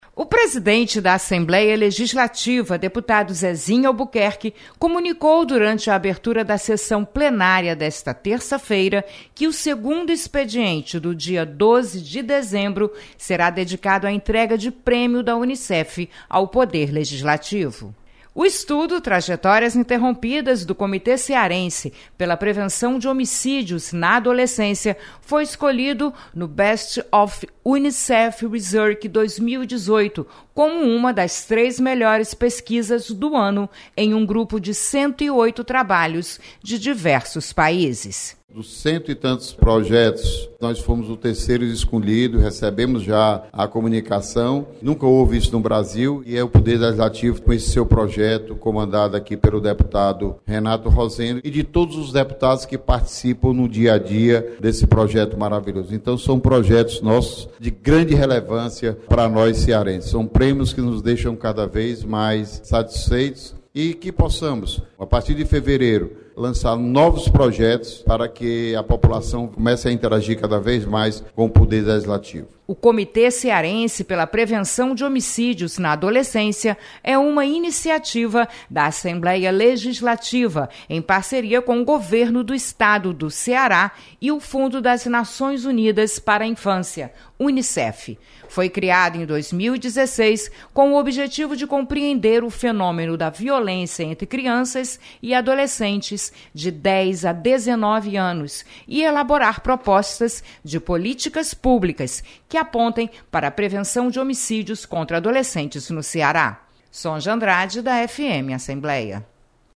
Presidente da Mesa Diretora, deputado Zezinho Albuquerque, anuncia sessão especial de premiação à Assembleia.